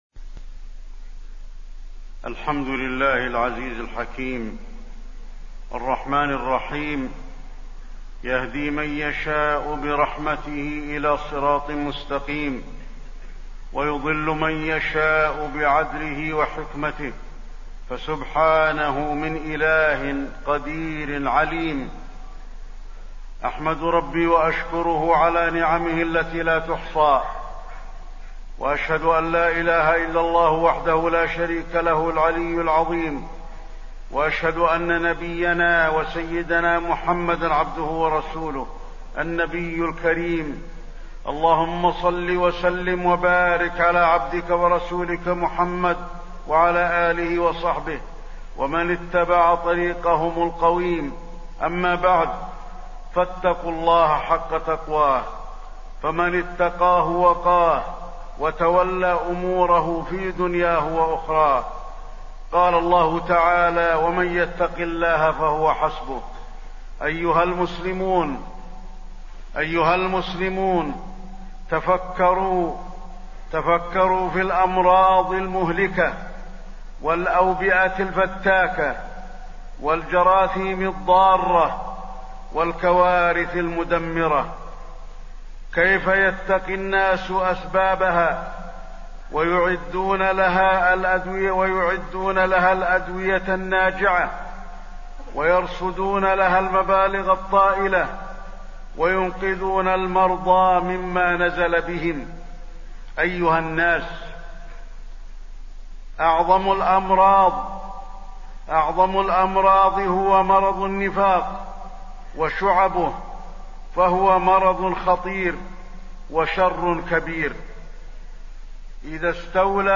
تاريخ النشر ٧ جمادى الآخرة ١٤٣١ هـ المكان: المسجد النبوي الشيخ: فضيلة الشيخ د. علي بن عبدالرحمن الحذيفي فضيلة الشيخ د. علي بن عبدالرحمن الحذيفي النفاق